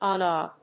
/ˈænə(米国英語), ˈɑ:nʌ(英国英語)/
フリガナアナ